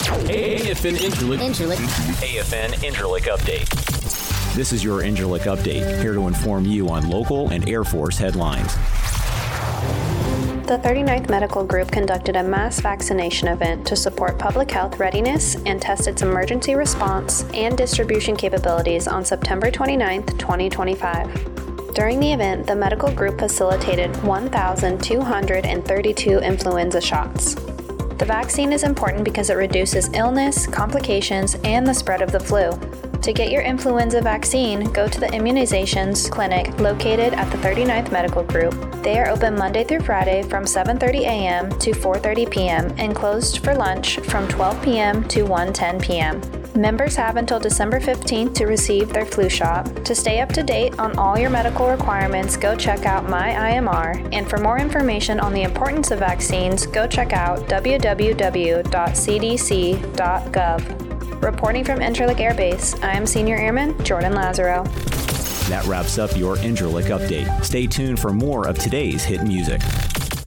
The following is a news update for AFN Incirlik at Incirlik Air Base, Turkey, Oct. 13, 2025. This newscast provided information on the mass vaccination event in support of public health readiness and testing the emergency response and distribution capabilities of the 39th Medical Group.